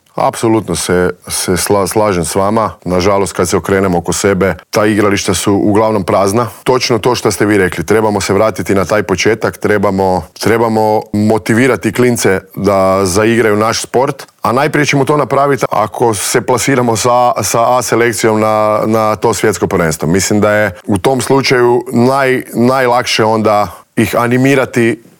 Iz godine u godinu se nadamo da će nam taj naš sport krenuti uzlaznom putanjom, a na čelo kao novi sportski direktor Hrvatskog košarkaškog saveza došao je tek umirovljeni igrač, Krunoslav Simon, koji je bio gost Intervjua tjedna Media servisa.